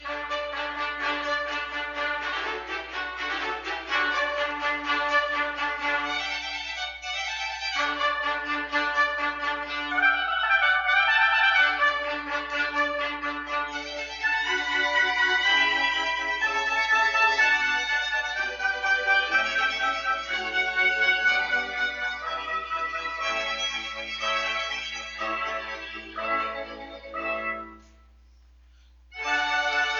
Fixing a "Tinny" recording
It’s running at the wrong speed, it’s at the wrong pitch and there’s zero bass notes.
this is the “official” recording of a live performance at our church.
There’s 60Hz power hum riding through the recording, so I need to get rid of that before anything else.
The bass problem is masking the missing treble, too.
And yes, it does sound like the conductor is in a hurry to go out for a beer.